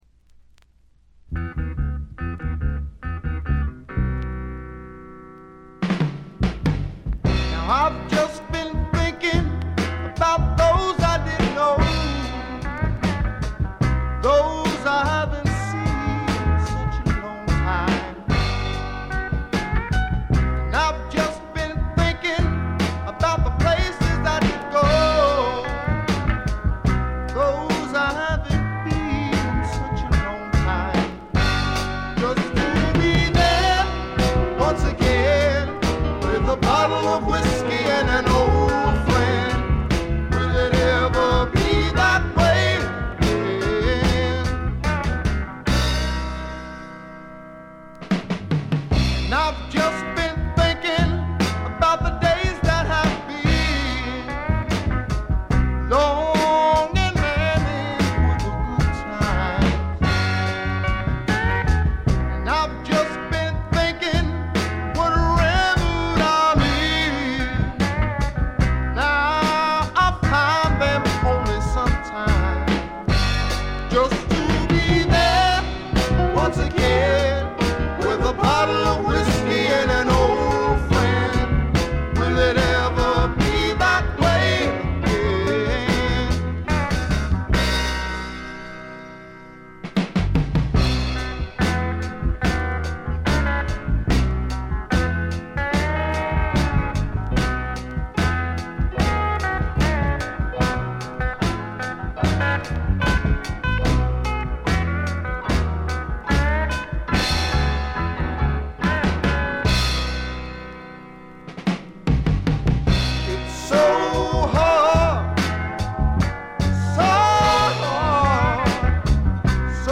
で、内容はザ・バンドからの影響が色濃いスワンプ裏名盤であります。
試聴曲は現品からの取り込み音源です。